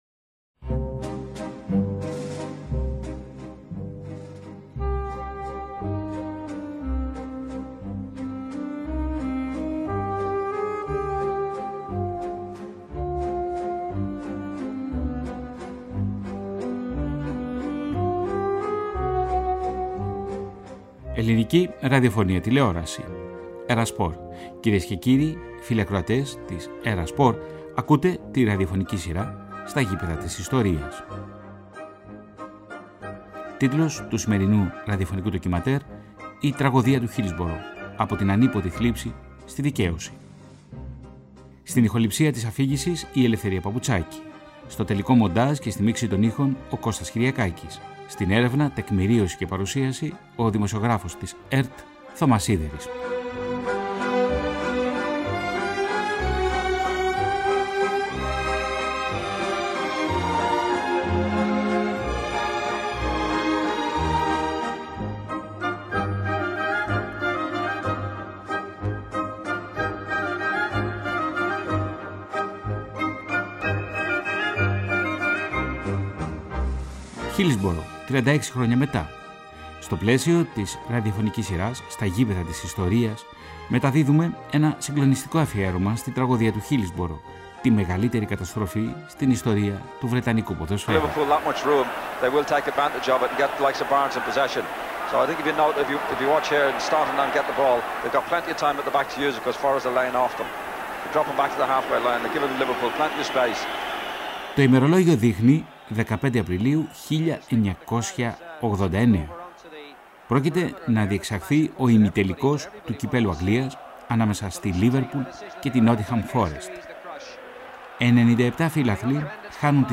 Το ραδιοφωνικό ντοκιμαντέρ ανέδειξε τον αγώνα των συγγενών των θυμάτων, που για περισσότερες από τρεις δεκαετίες πάλεψαν για την αναγνώριση της αλήθειας και την αποκατάσταση της μνήμης των ανθρώπων τους.
Ένα ραδιοφωνικό ντοκιμαντέρ για το χρονικό της τραγωδίας, το αδυσώπητο κυνήγι της δικαιοσύνης και τη δικαίωση που άργησε να έρθει.